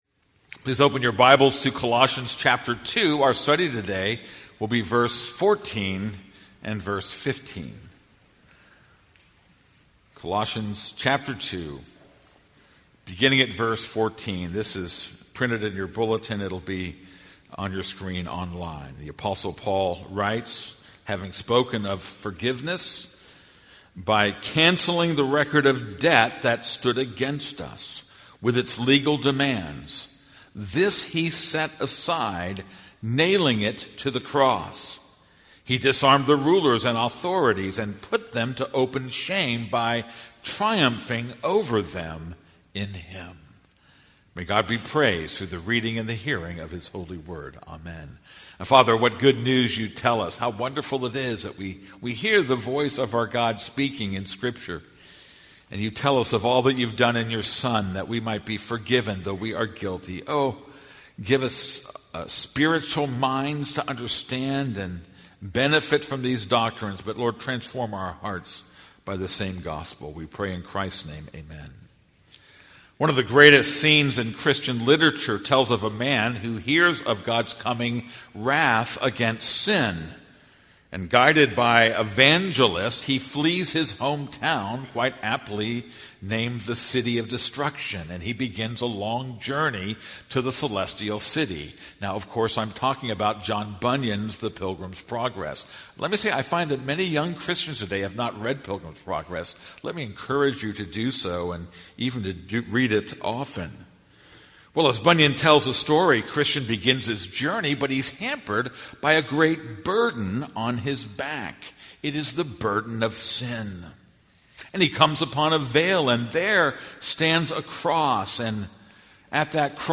This is a sermon on Colossians 2:14-15.